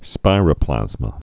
(spīrə-plăzmə)